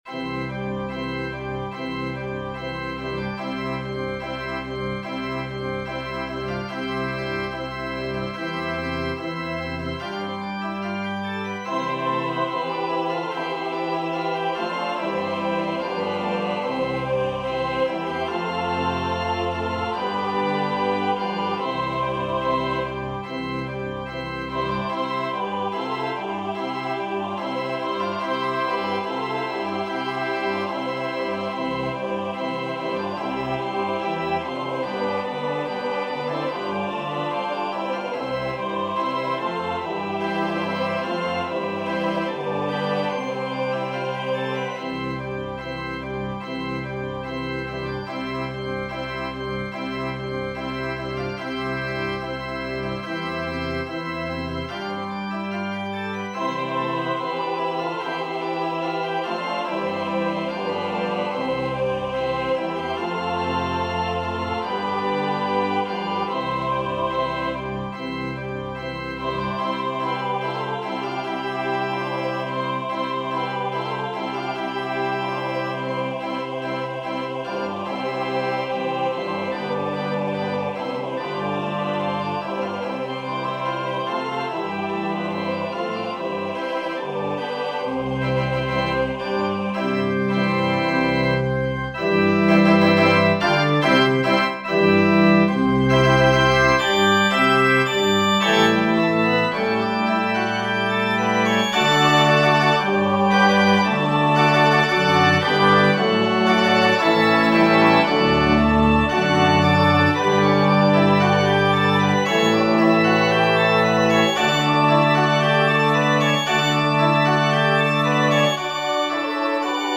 Voicing/Instrumentation: SATB , Organ/Organ Accompaniment
Choir with Congregation together in certain spots